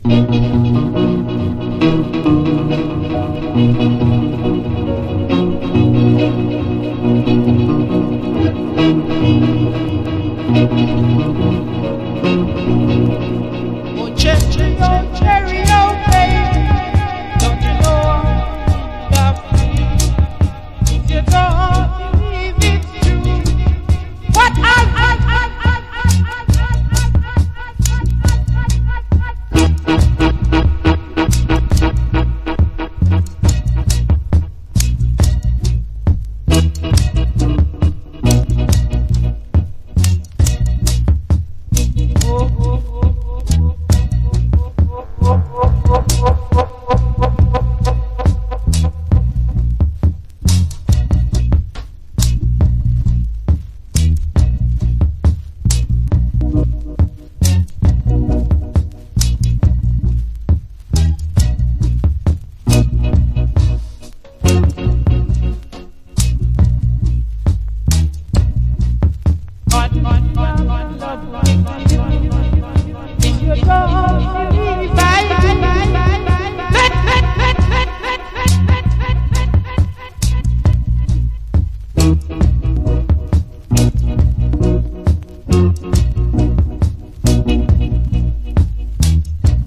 • REGGAE-SKA
# ROOTS# DUB / UK DUB / NEW ROOTS